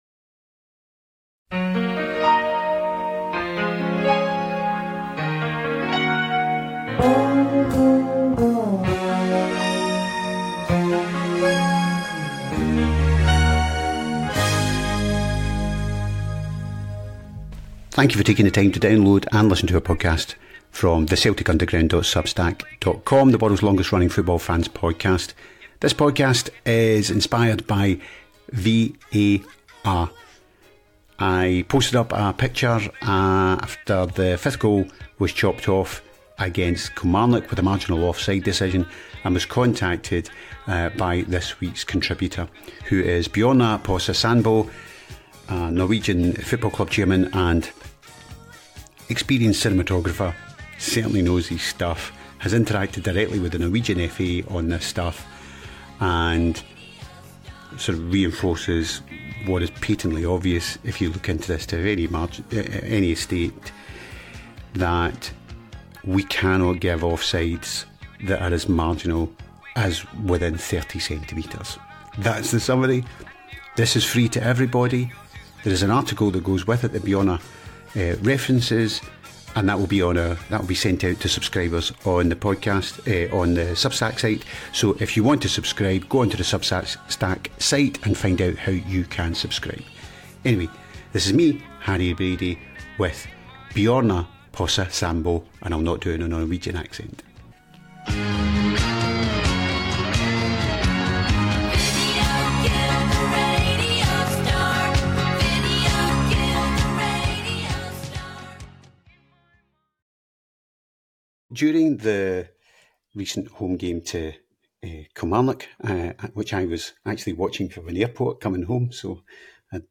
This conversation delves into the complexities and controversies surrounding VAR (Video Assistant Referee) technology in football, particularly focusing on offside decisions.